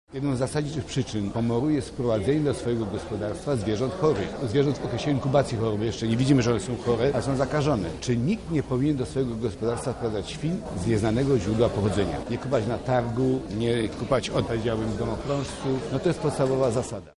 Odbyło się ono w dziś w Lubelskim Urzędzie Wojewódzkim.